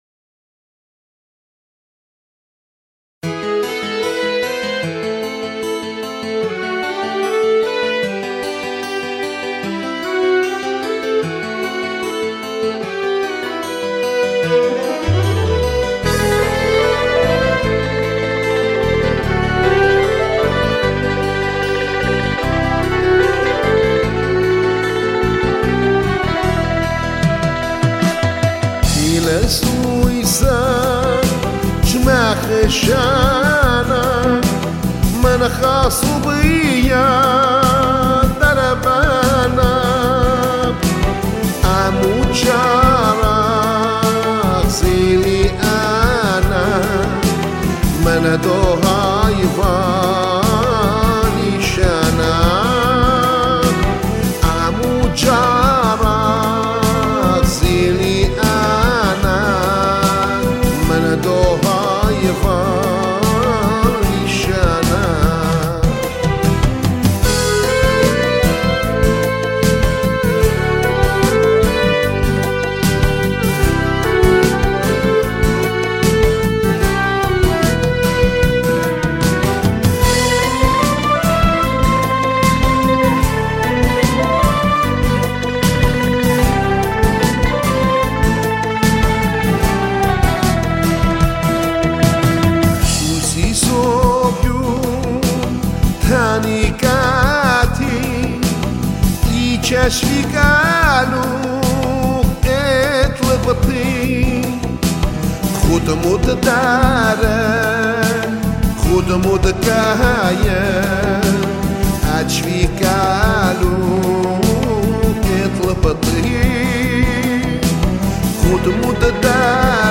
Zurna
Clarinet
and is also a singer.